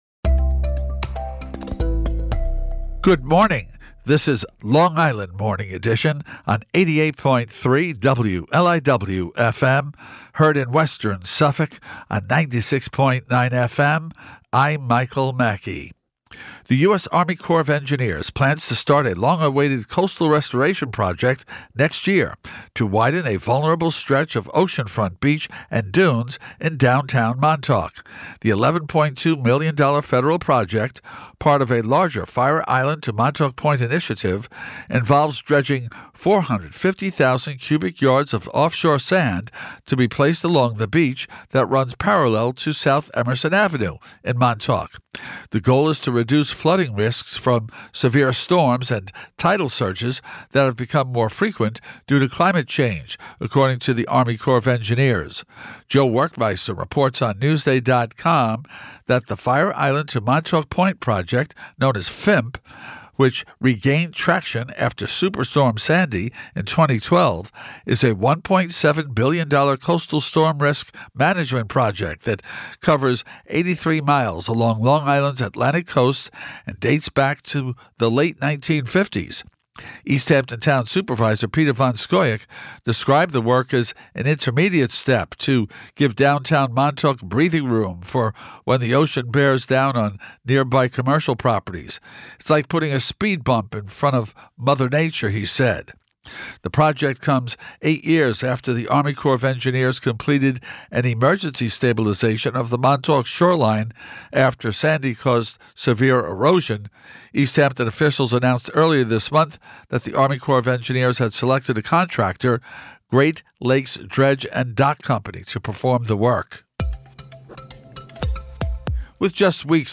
newscast.mp3